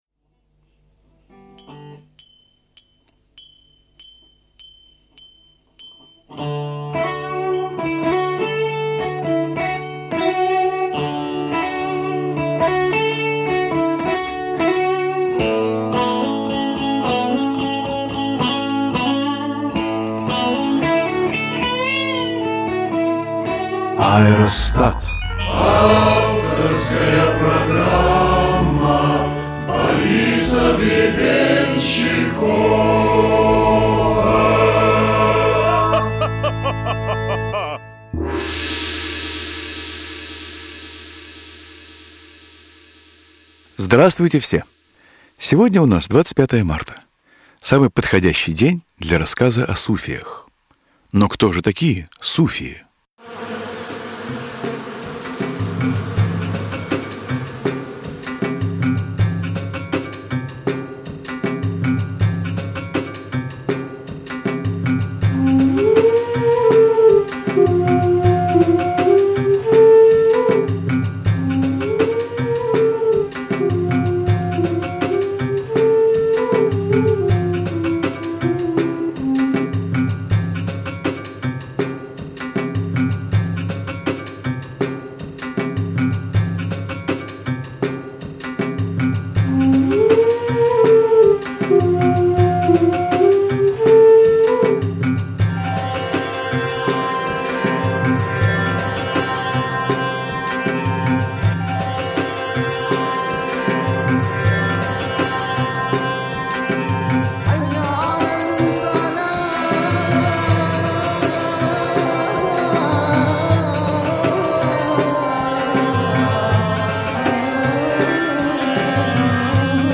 Аэростат - “Слово о суфиях” (с музычкой) /uploads/default/original/2X/7/73df02259d4a237558ca456d587ed95cfb979e7e.mp3